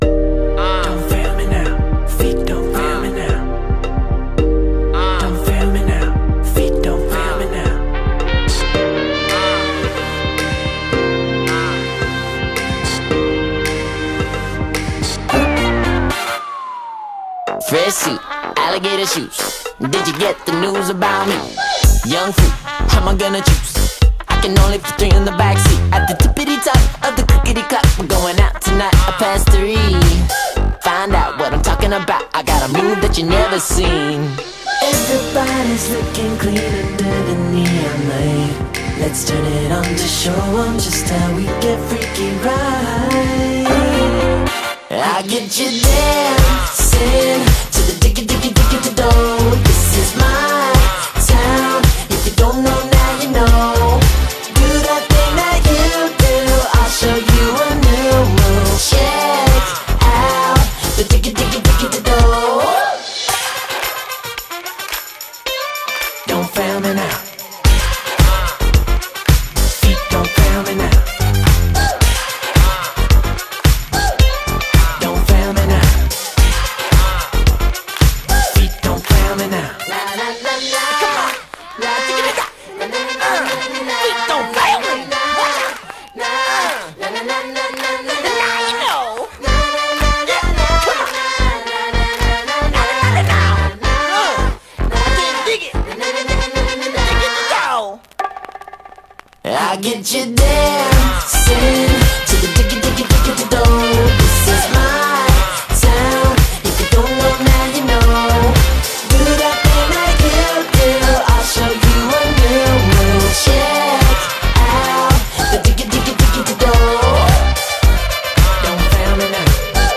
BPM110
MP3 QualityMusic Cut